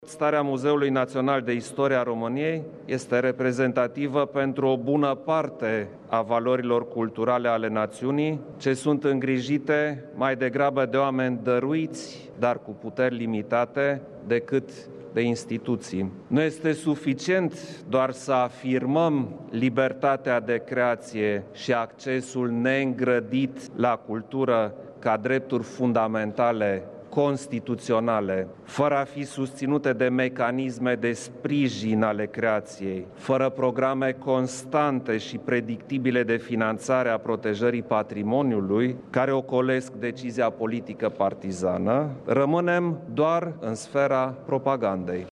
Preşedintele Iohannis a subliniat că multe instituţii de cultură din ţară sunt prost întreţinute, din cauza subfinanţării: